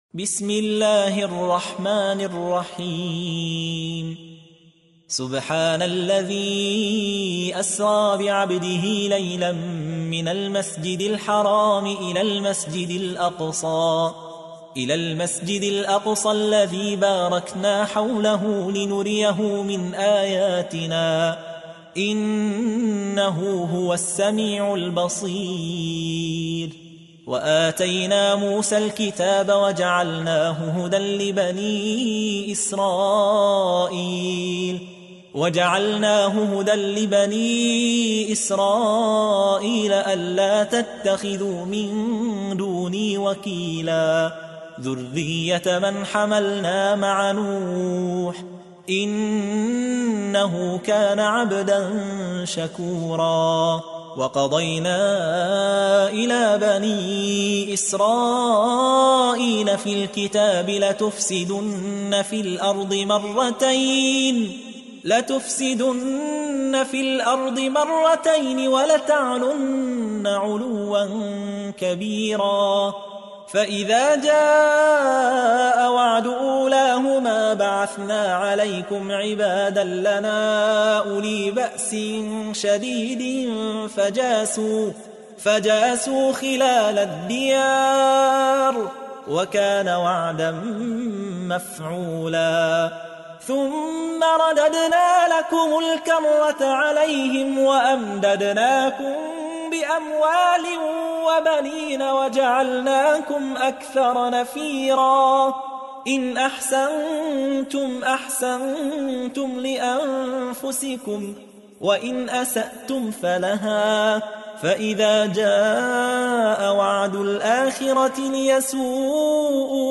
تحميل : 17. سورة الإسراء / القارئ يحيى حوا / القرآن الكريم / موقع يا حسين